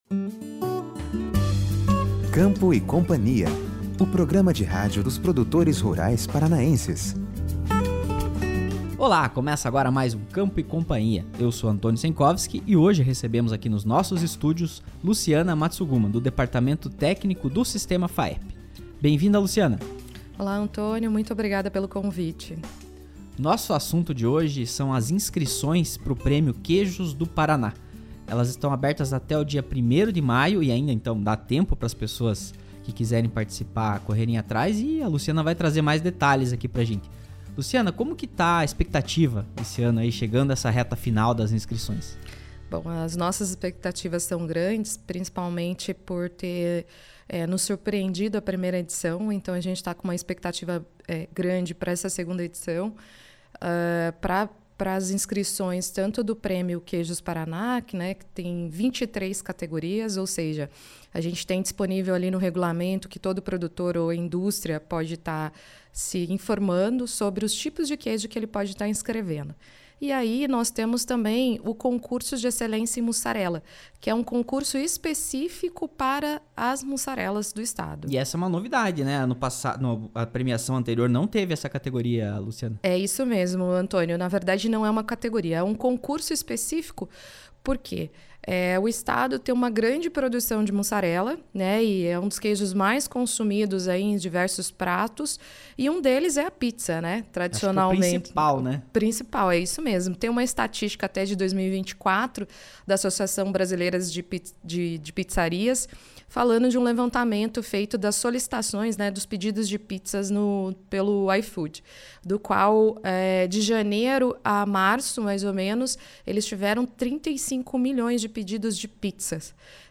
concedeu entrevista ao episódio #237 do programa de rádio “Campo & Cia”, produzido pelo Sistema Faep/Senar-PR: